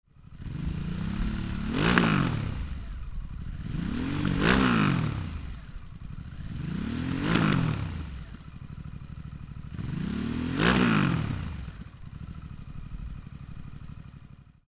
Escape Slip-On HR-1 Negro diseñado específicamente para su instalación en HARLEY DAVIDSON SPORTSTER-S 2021+